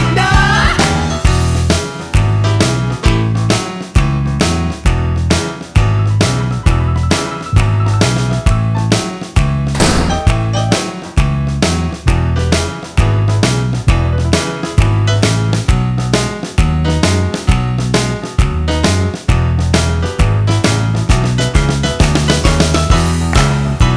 No Guitars Pop (1980s) 3:21 Buy £1.50